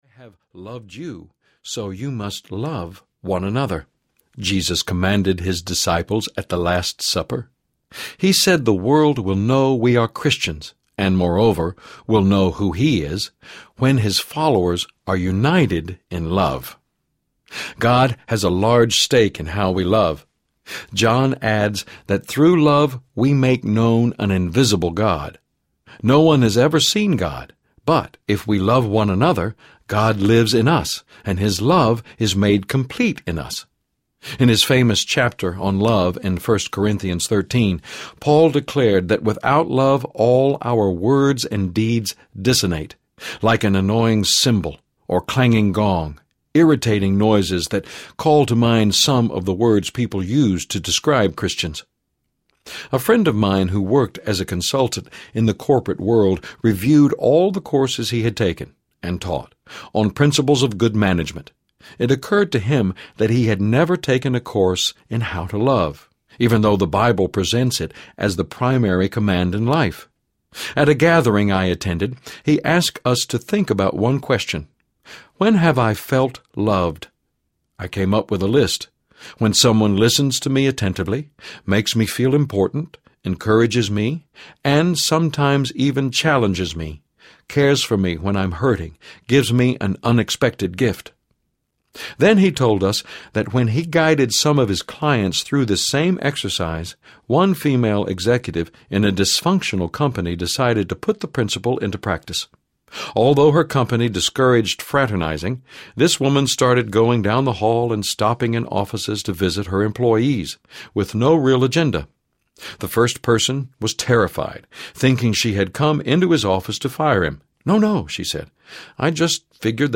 Vanishing Grace Audiobook